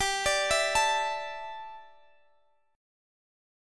G 6th Suspended 2nd (page 4)